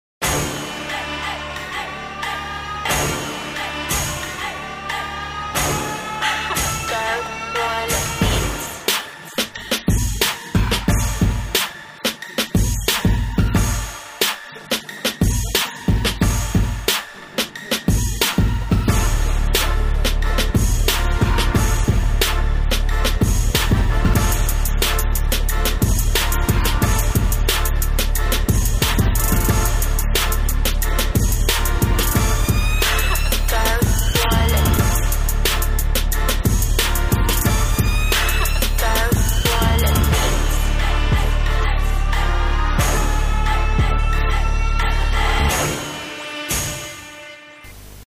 Совсем голос на бите не звучит, запись, видимо, полностью подвела, и флоу неуверенный, и подачи недостаёт.
Блеклый голос, нужно с большей силой подавать